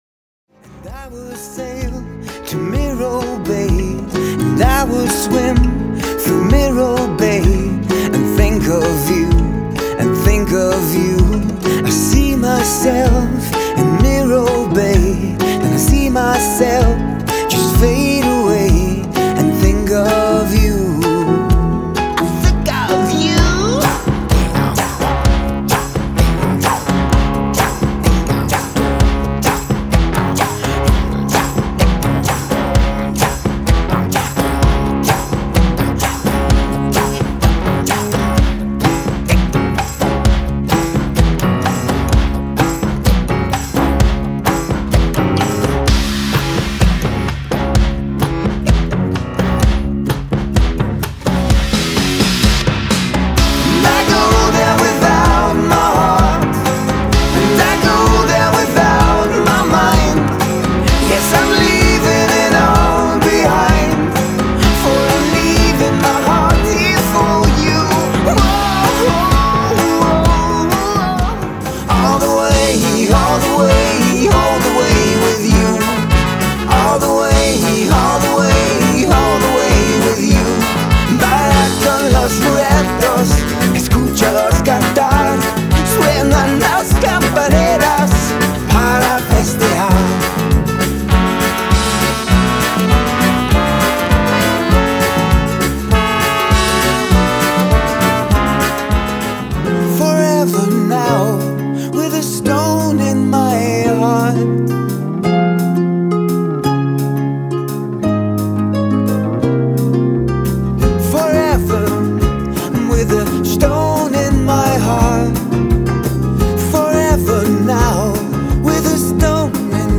Pop / Indie / Worldmusic